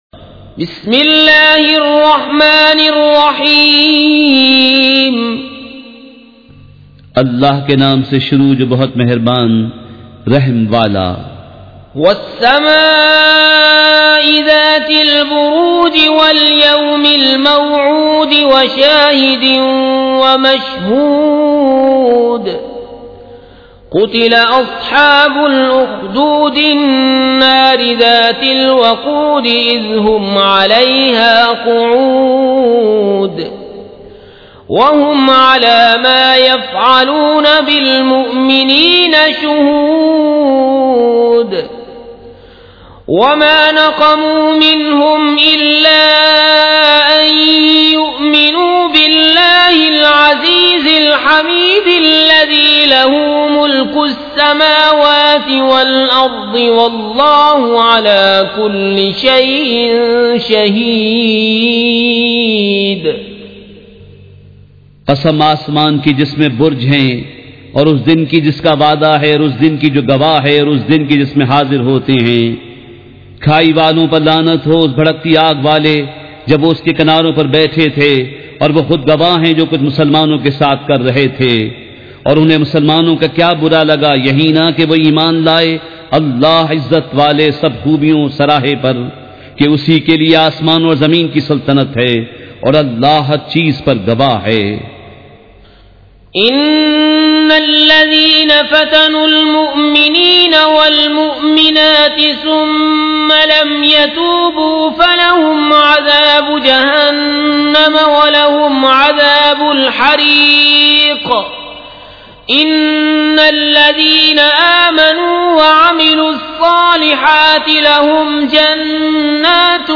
سورۃ البروج مع ترجمہ کنزالایمان ZiaeTaiba Audio میڈیا کی معلومات نام سورۃ البروج مع ترجمہ کنزالایمان موضوع تلاوت آواز دیگر زبان عربی کل نتائج 1787 قسم آڈیو ڈاؤن لوڈ MP 3 ڈاؤن لوڈ MP 4 متعلقہ تجویزوآراء